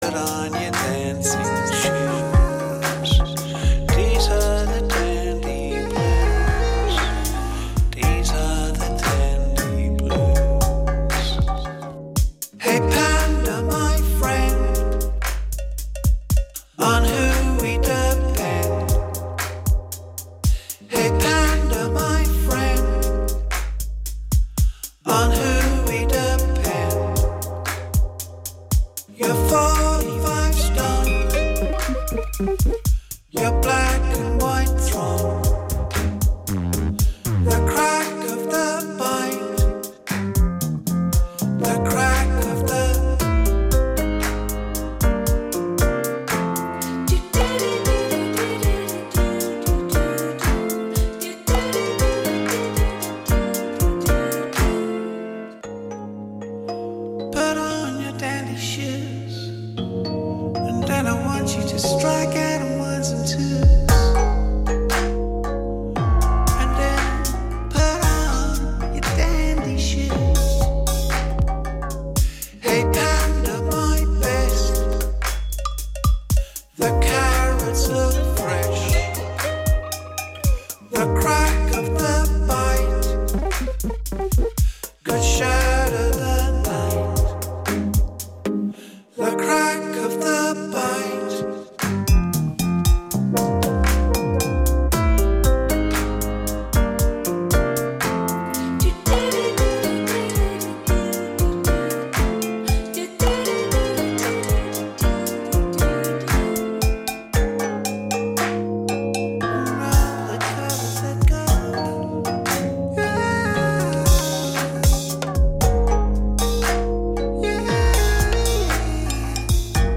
Pořad o knižních novinkách, čtení z knih, soutěže, rozhovory.